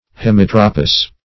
Search Result for " hemitropous" : The Collaborative International Dictionary of English v.0.48: Hemitropal \He*mit"ro*pal\, Hemitropous \He*mit"ro*pous\, a. [See Hemitrope .] 1.